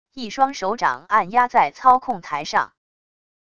一双手掌按压在操控台上wav音频